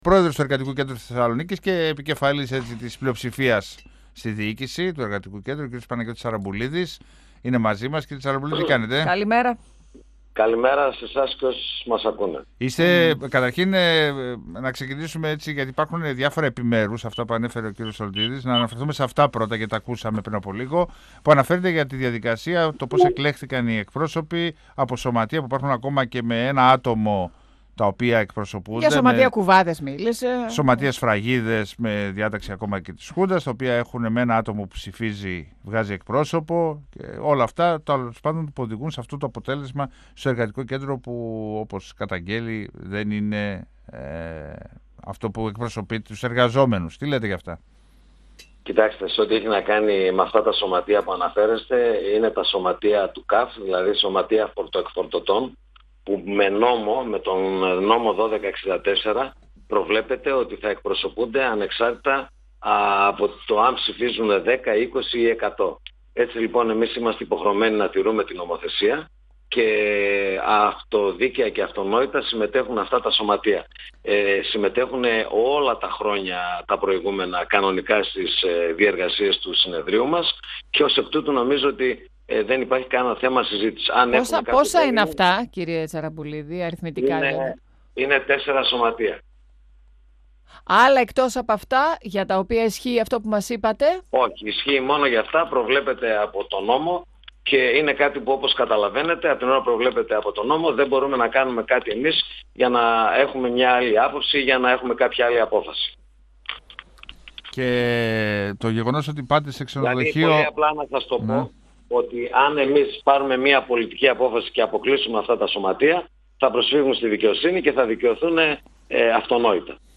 στον 102 fm της ΕΡΤ3